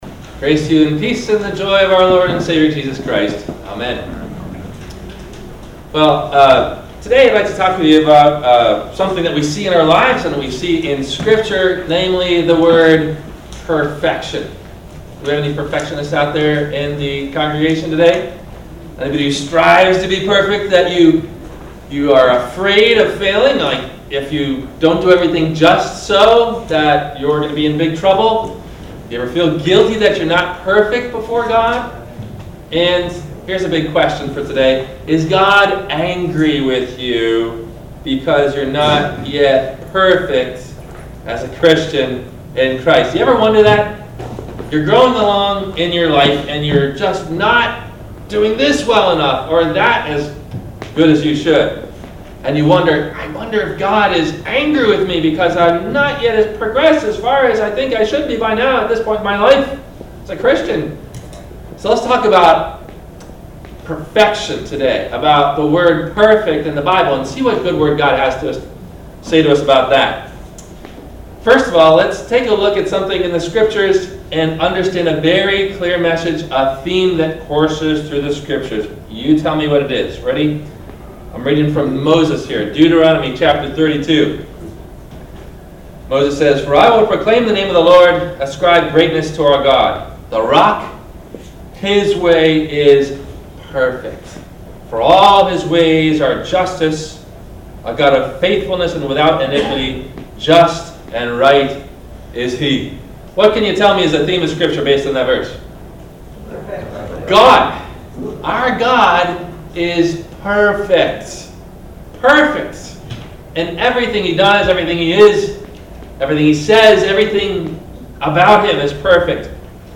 What if I am Not Perfect? - Sermon - July 24 2016 - Christ Lutheran Cape Canaveral